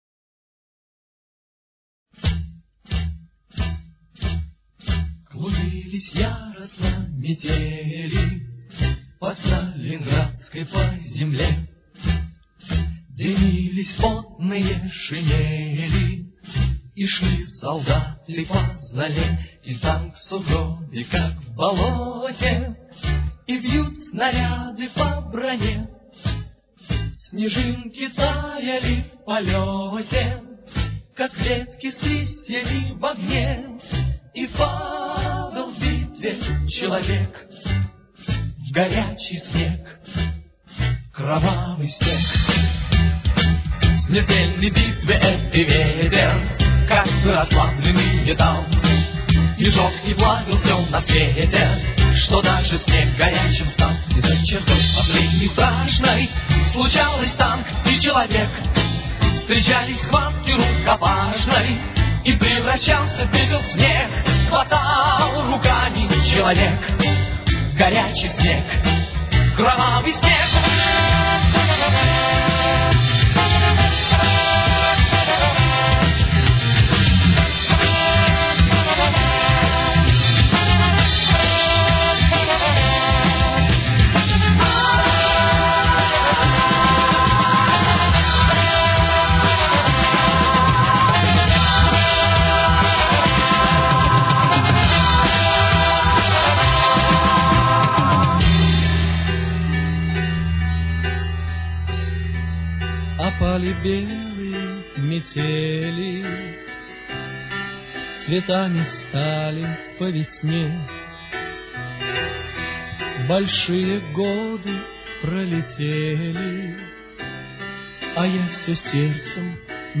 Тональность: Ми минор. Темп: 96.